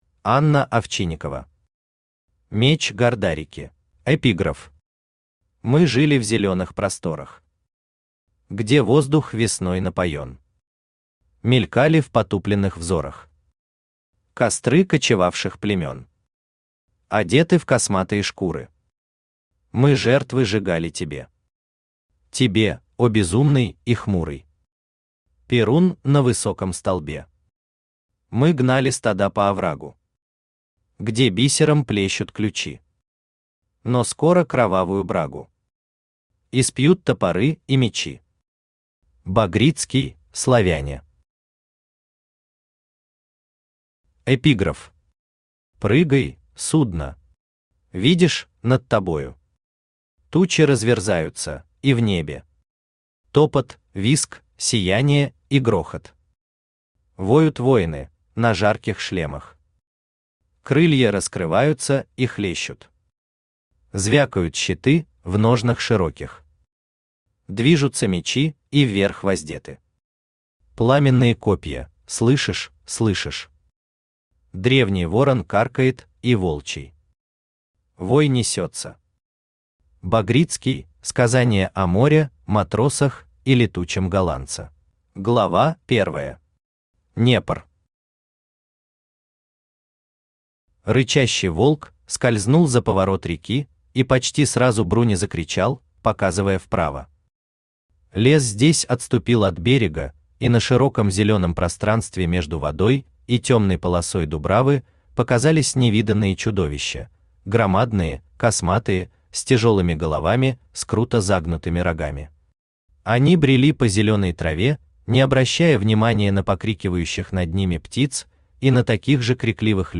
Аудиокнига Меч Гардарики | Библиотека аудиокниг
Aудиокнига Меч Гардарики Автор Анна Овчинникова Читает аудиокнигу Авточтец ЛитРес.